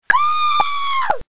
Woman Screaming Sound Effect Wav 13
100 percent royalty free woman screaming sound effect (Time Length 1.3 seconds).
Preview contains security watermark beeps - the downloadable wav file is broadcast quality without watermarking.
scream013sample.mp3